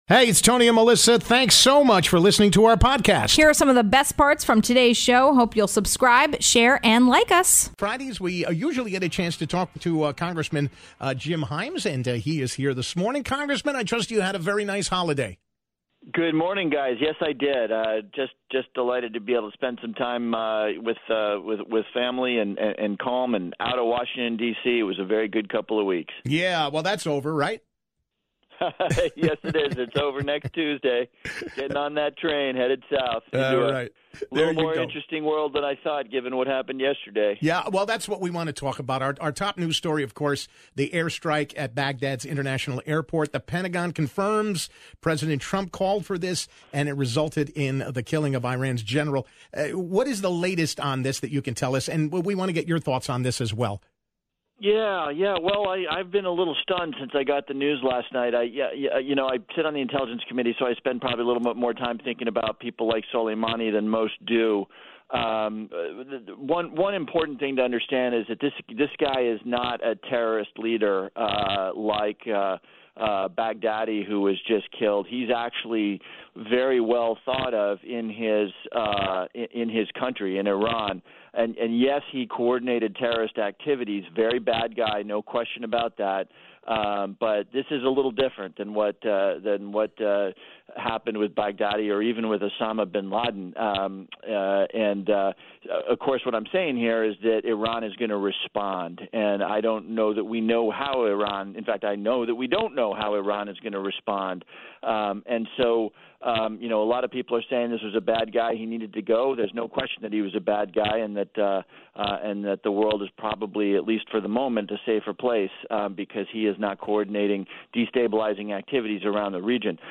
1. Congressman Jim Himes talks about the deadly air strike overseas.